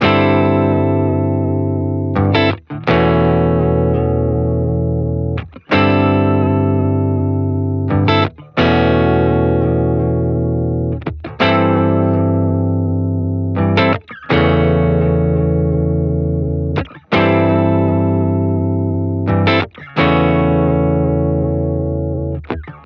PseudoJazz.wav